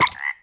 1.21.5 / assets / minecraft / sounds / mob / frog / hurt3.ogg
hurt3.ogg